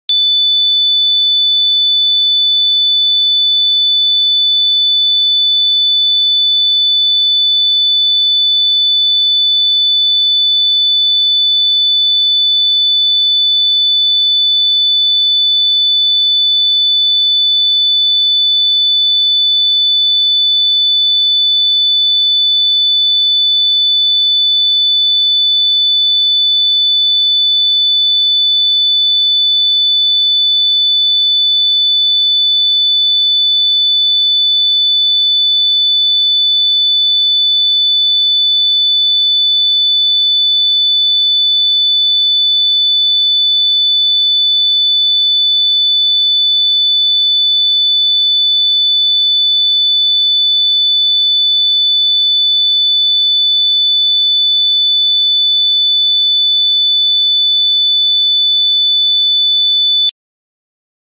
Feel the power of 3498 Hz! Allow yourself a 60-second break, immerse in the sound, and comment your sensations.